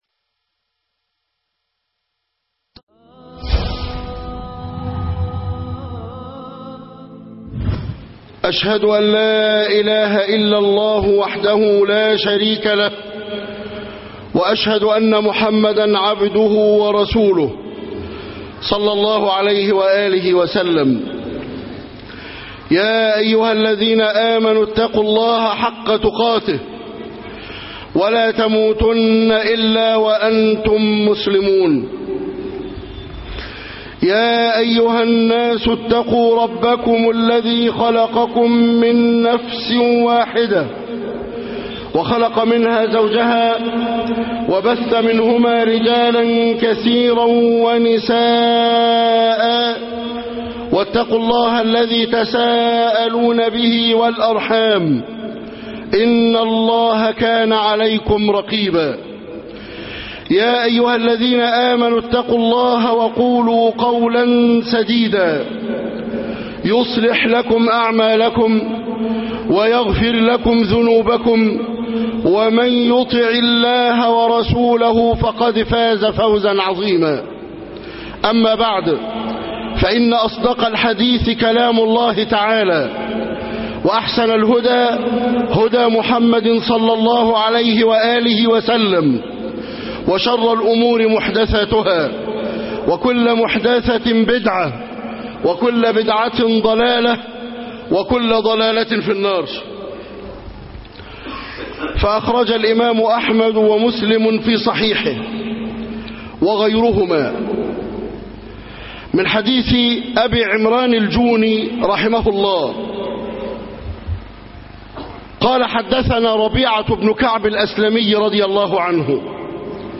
كف الخطب بشرح حديث ربيعة بن كعب- خطب الجمعة